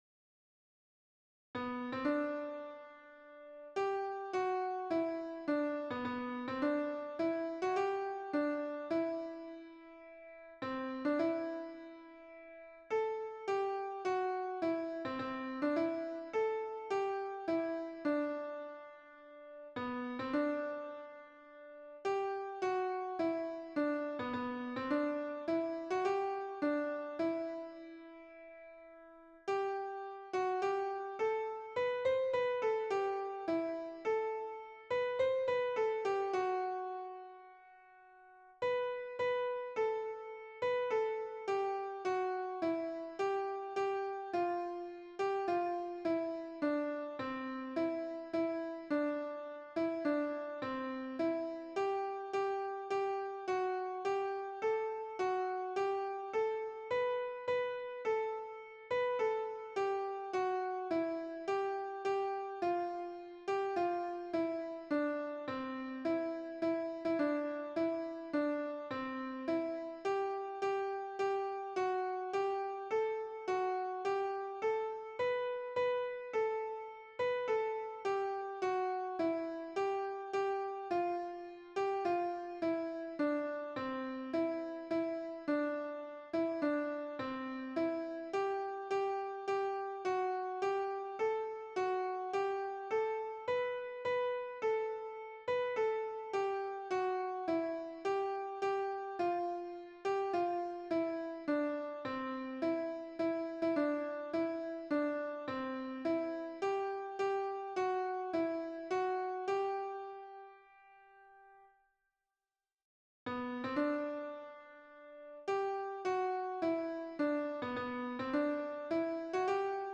02-hymn-ii-lo-fortepian-tylko-melodia-instrumentalnie.mp3